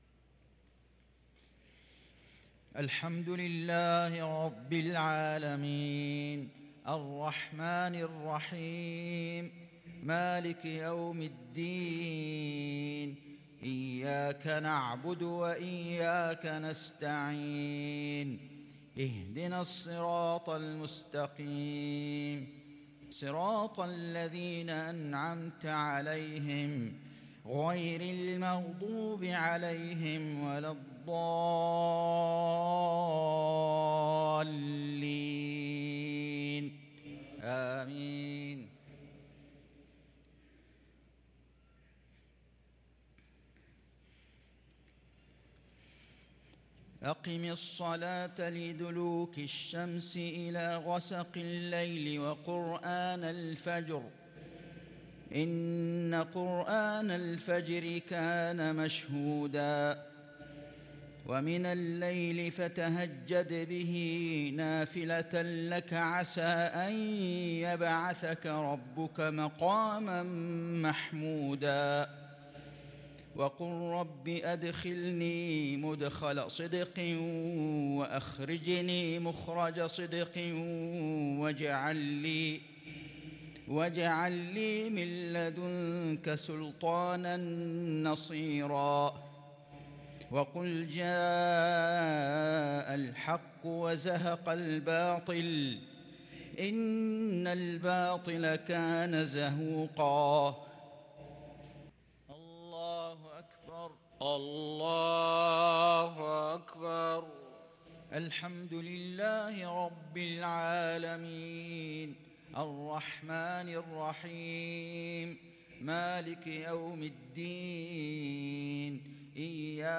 صلاة المغرب للقارئ فيصل غزاوي 21 محرم 1444 هـ
تِلَاوَات الْحَرَمَيْن .